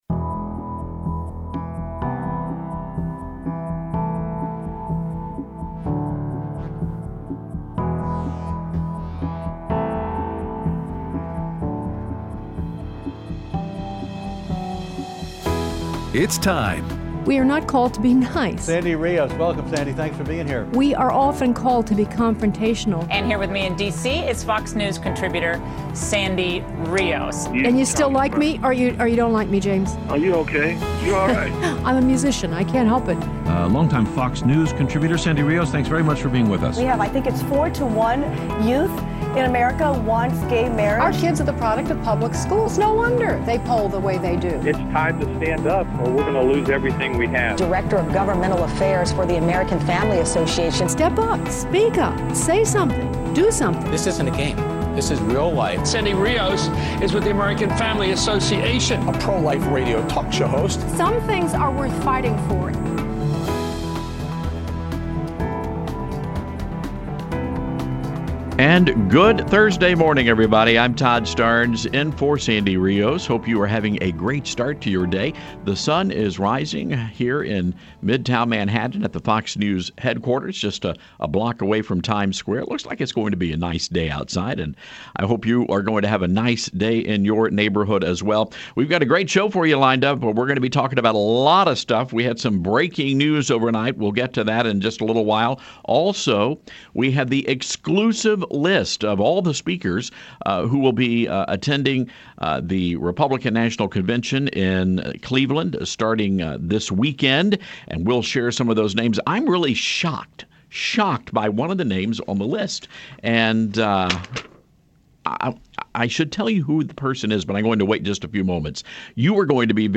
Show Notes Todd Starnes talks and takes phone calls on Vice President choices